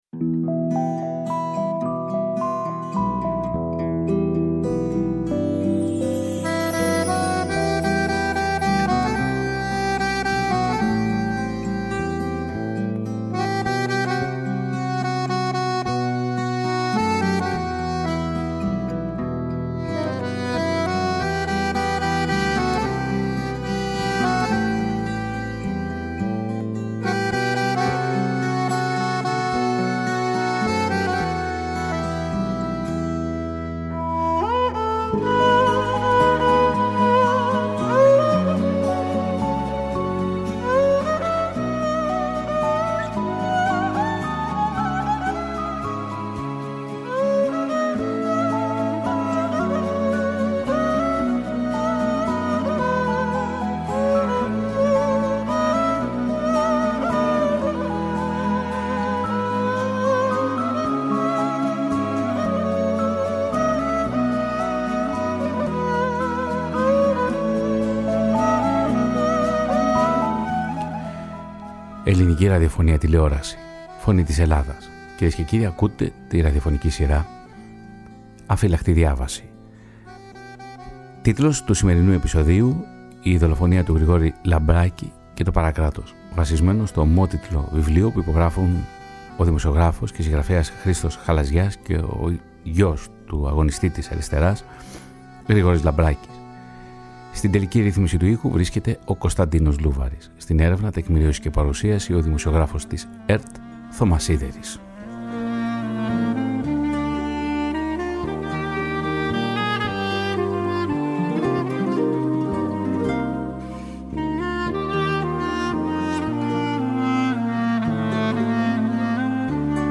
Κύριοι αφηγητές του ραδιοφωνικού ντοκιμαντέρ
οι οποίοι βρέθηκαν στον κεντρικό ραδιοθάλαμο της Φωνής της Ελλάδας.